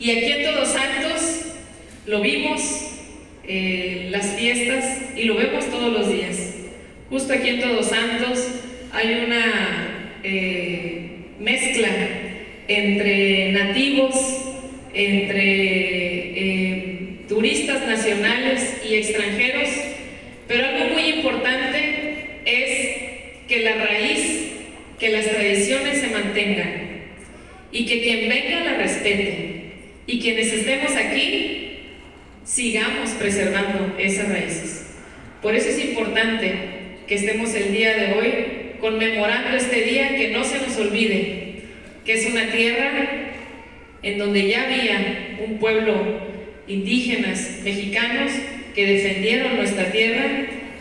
Teniendo como escenario el Teatro “Manuel Márquez de León” de Todos Santos, el sábado por la mañana se llevó a cabo la ceremonia conmemorativa del DXXXII Encuentro de Dos Mundos y el Día de la Nación Pluricultural, con la presencia de autoridades civiles y militares, encabezadas por la Presidenta Municipal de La Paz, Milena Quiroga Romero.